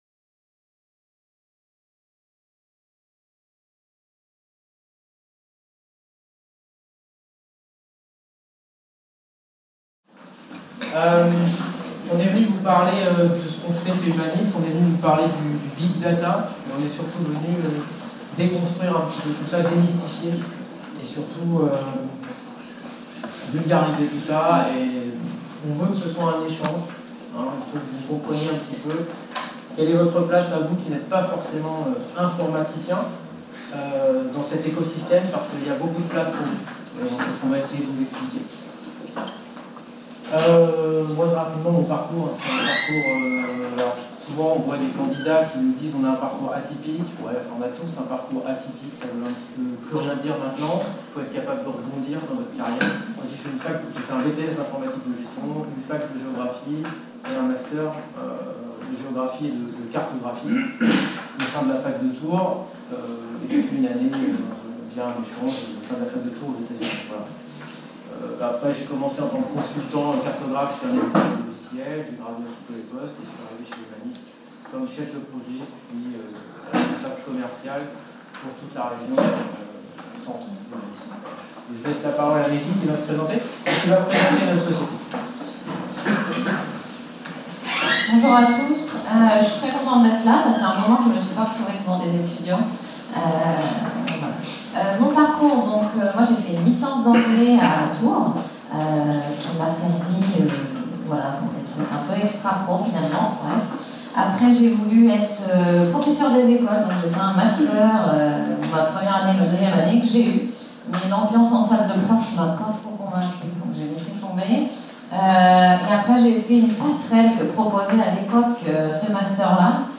Conférence organisée par le Master d'économie de la Faculté de Tours.